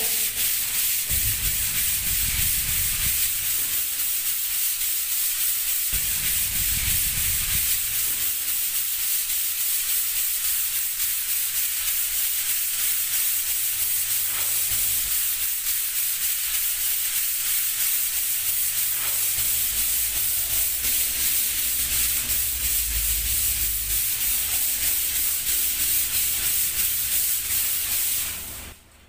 Tiếng giấy Nhám chà Tường, chà giấy giáp… sơn tường, xây dựng
Thể loại: Tiếng động
Description: Âm thanh xoẹt xoẹt, tiếng trà giấy nhám, tiếng mài giấy giáp, tiếng đánh bóng tường, tiếng chà bề mặt, tiếng xả tường. đặc trưng khi bề mặt giấy nhám (giấy giáp, giấy nháp) ma sát với tường, tạo cảm giác nhám, khô và liên tục. Đây là âm thanh quen thuộc trong công việc sơn sửa, xây dựng, dùng để làm mịn bề mặt trước khi sơn phủ.
tieng-giay-nham-cha-tuong-cha-giay-giap-son-tuong-xay-dung-www_tiengdong_com.mp3